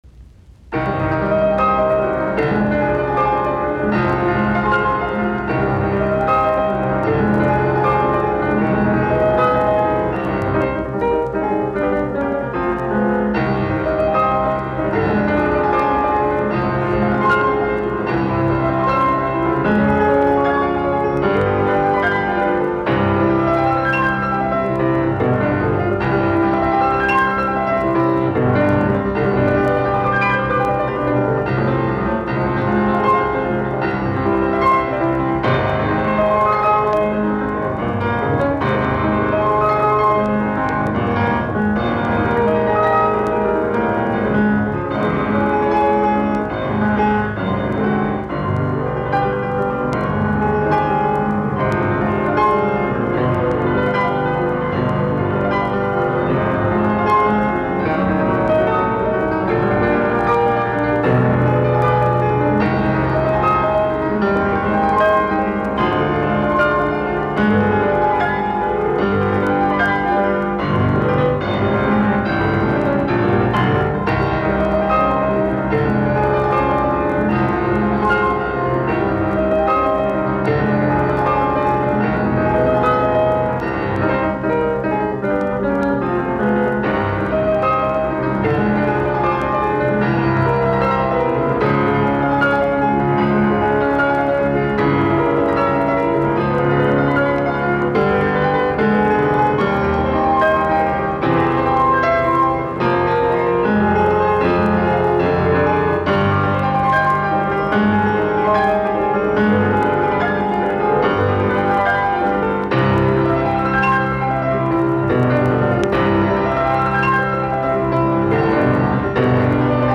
Etydit, piano, op25